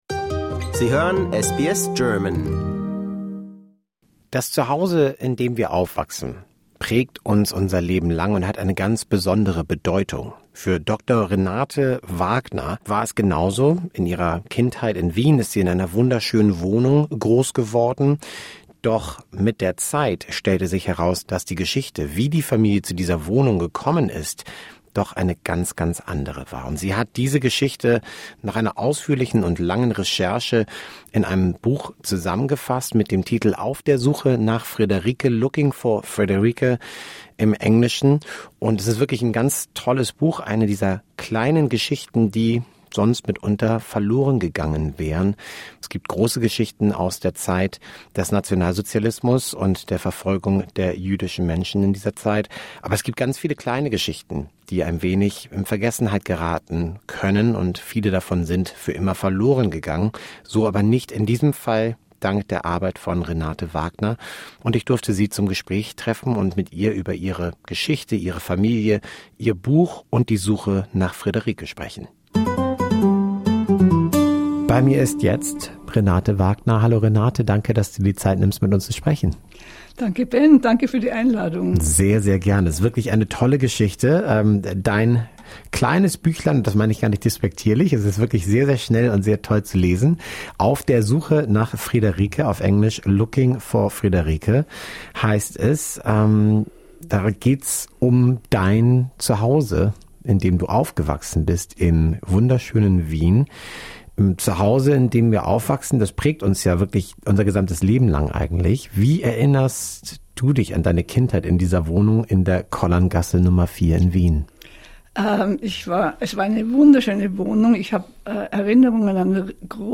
Disclaimer: We would like to point out that the opinions expressed in this article represent the personal views of the interviewed/interlocutor.